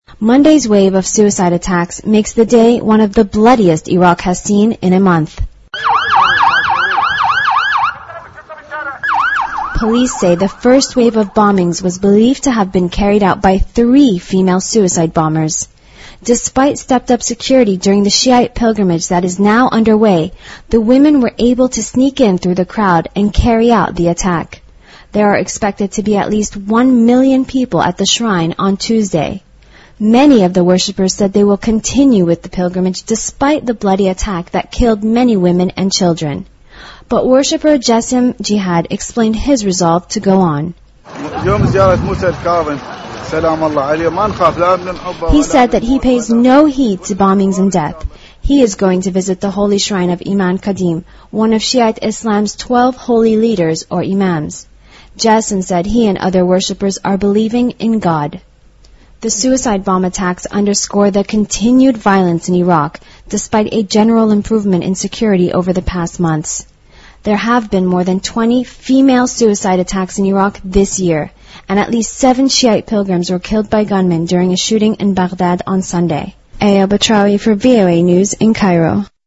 位置：首页 > 英语听力 > 英语听力教程 > 英语新闻听力